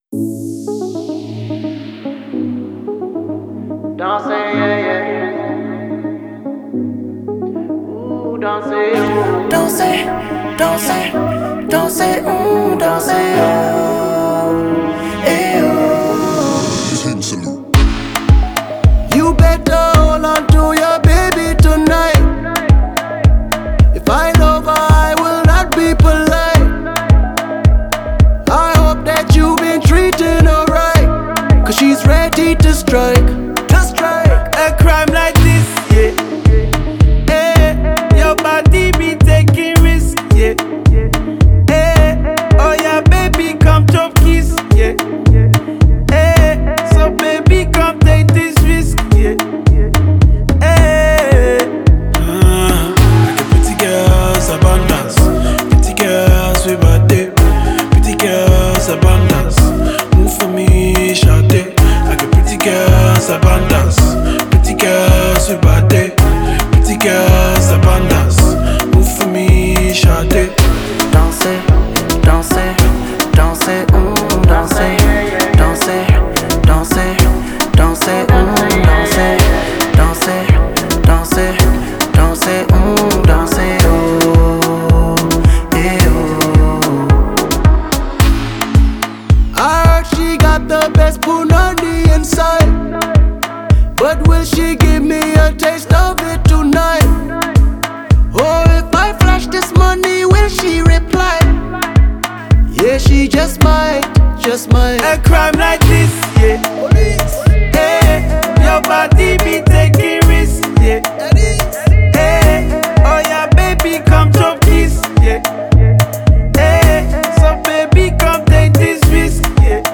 это яркая и энергичная песня в жанре поп и R&B